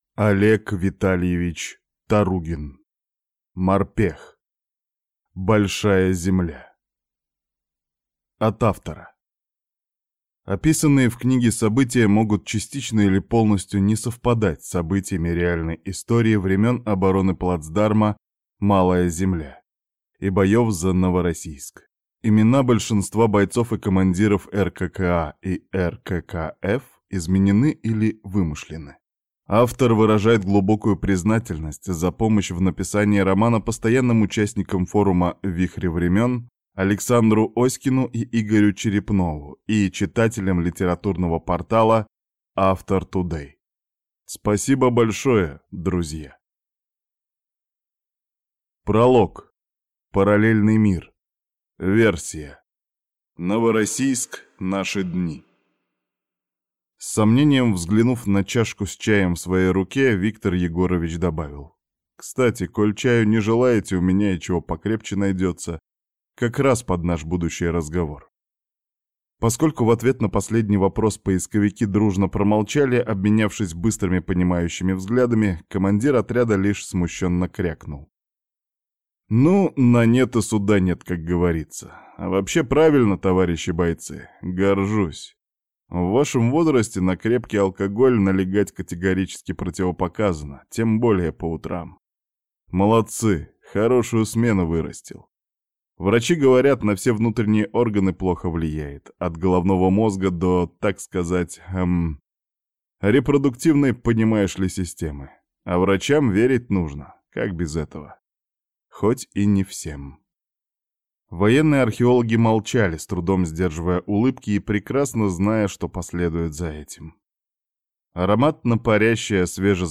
Аудиокнига Морпех. Большая земля | Библиотека аудиокниг